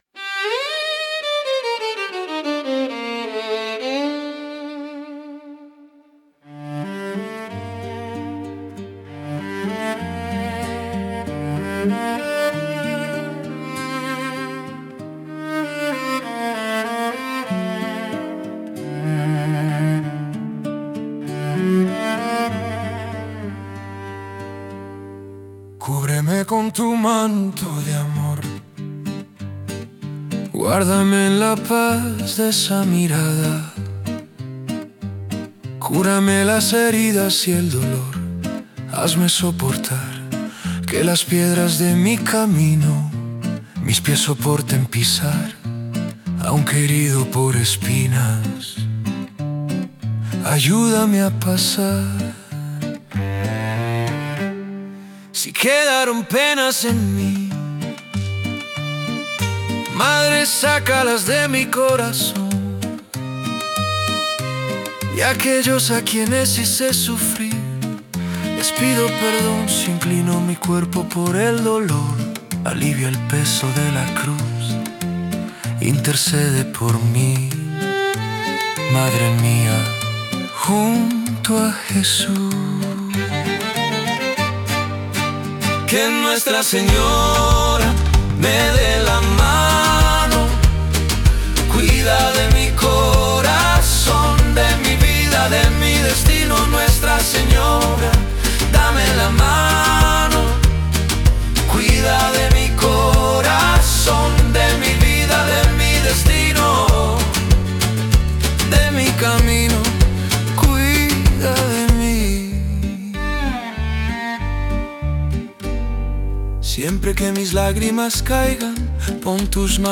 música e arranjo: IA) instrumental 5